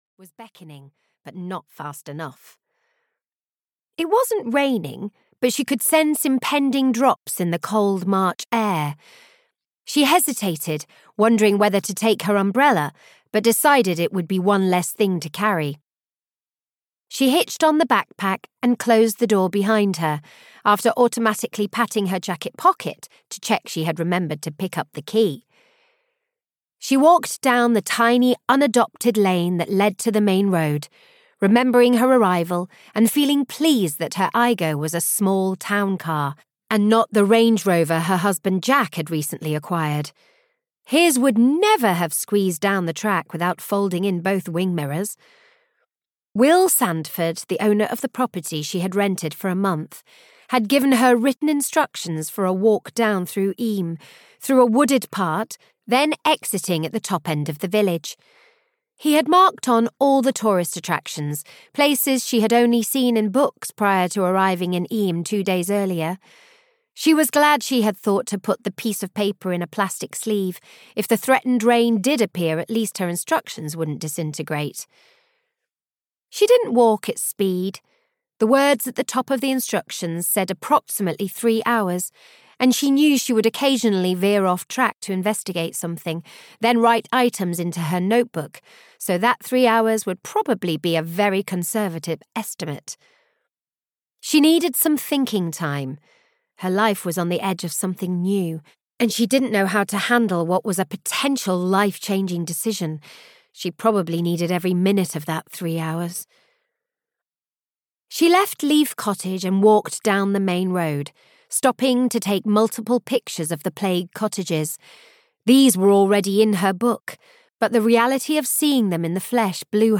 Mortal Green (EN) audiokniha
Ukázka z knihy